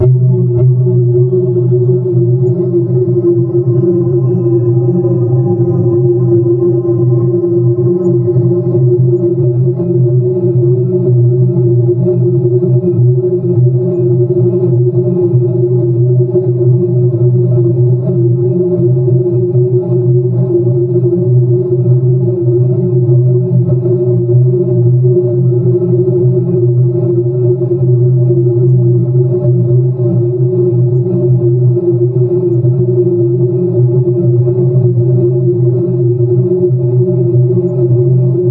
恐怖的声音效果库 " Ambience Monsters Belly 00
描述：在恐怖游戏中使用的黑暗和令人毛骨悚然的环境声音。有用的是被困在一个巨大的邪恶怪物里，或者有一场噩梦。
Tag: 恐惧 gamedeveloping 可怕的 史诗 indiedev 游戏 视频游戏 氛围 篮板 面目可憎 gamedev 游戏 幻想 可怕的 恐怖 吓人 SFX 视频游戏 游戏 indiegamedev